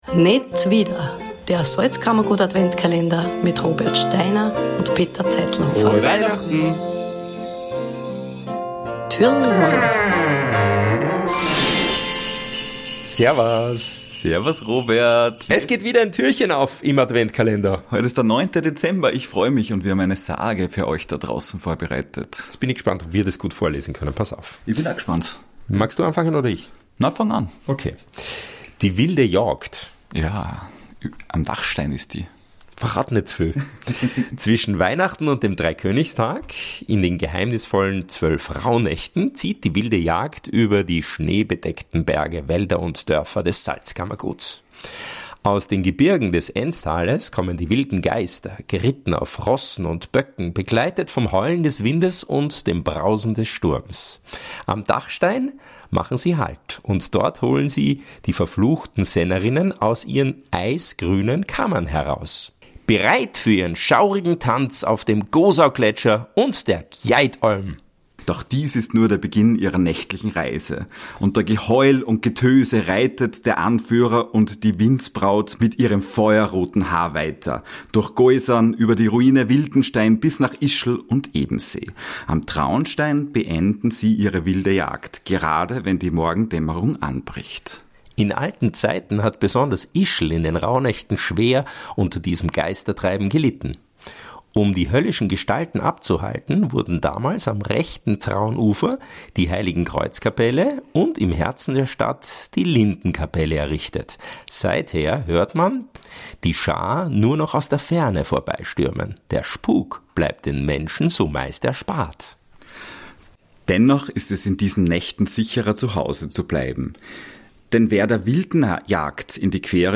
Heute lesen euch die beiden die alte Sage der Wilden Jagd vor.